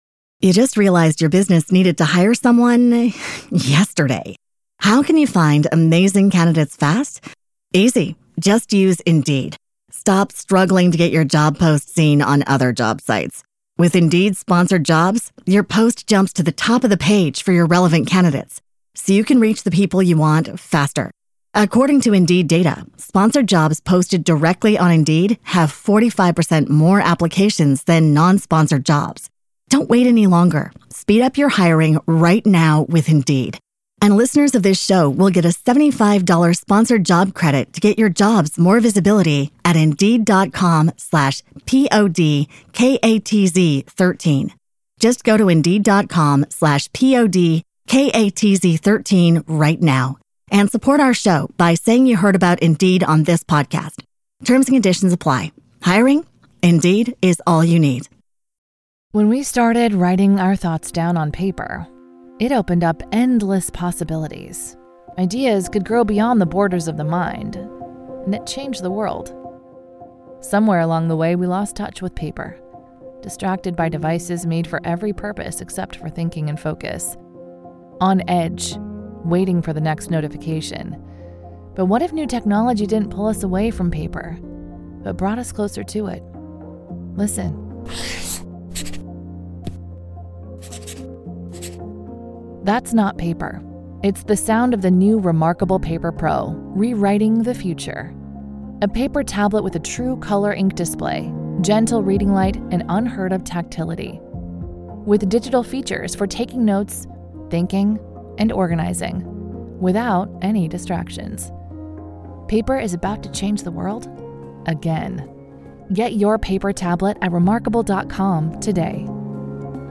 Narratives of Change and Growth - Powerful Motivational Speech.